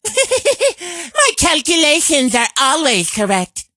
carl_kill_vo_01.ogg